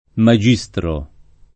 maJ&Stro] s. m. — raro latinismo ant. per maestro (anche nel senso di «magistrato»): deprimere l’autorità del magistro [